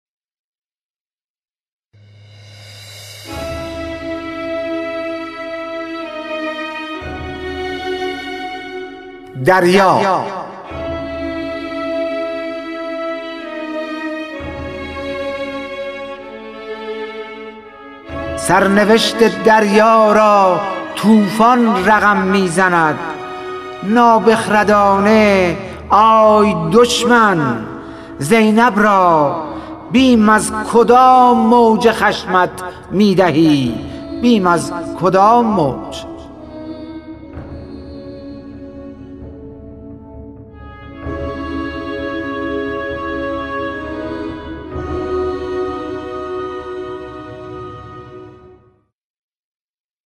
خوانش شعر سپید عاشورایی / ۳